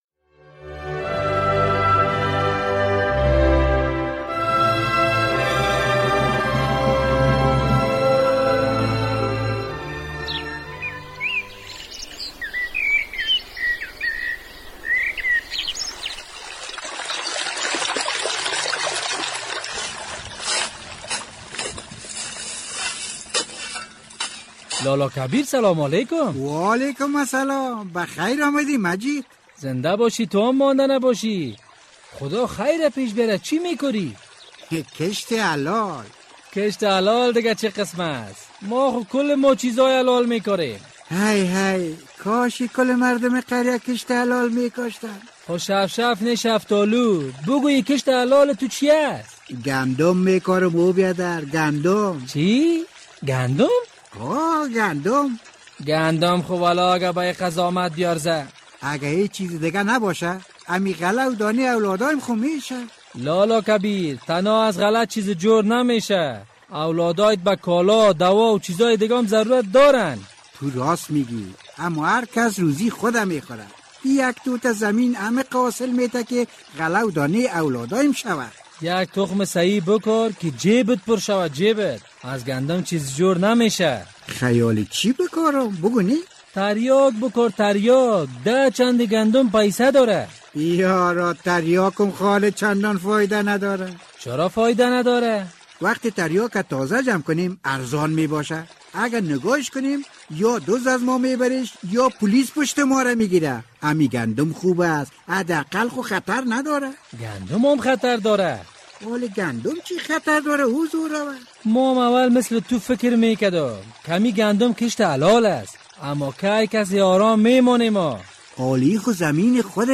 درامه، تک تک دروازه در نصف شب کبیر لالا را پریشان کرد